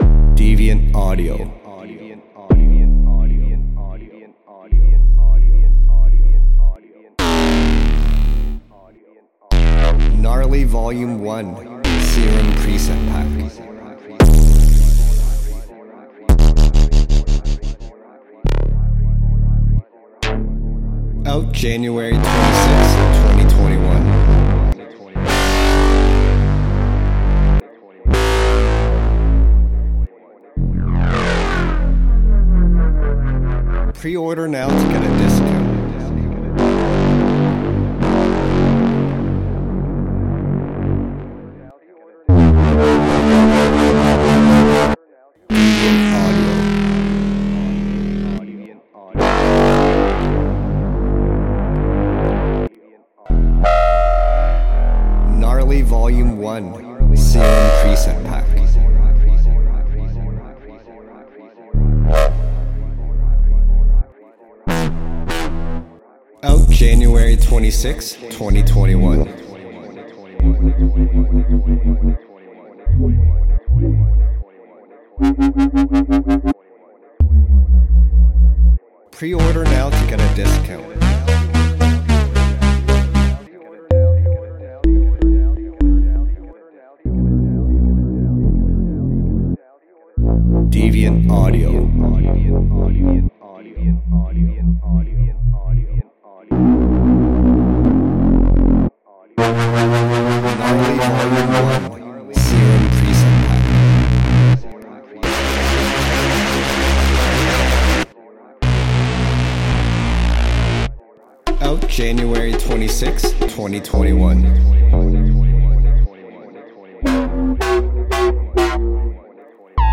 对于需要低沉的低音，巨大的号角，坚韧的里斯，粗糙的808，跳跃的摇摆声，摇摆和刺耳的声音的人，Gnarly是一个不错的选择。它针对想要在Drum＆Bass，Jungle，Bassline，UK Bass甚至Electro / EDM中具有前置音色的任何人。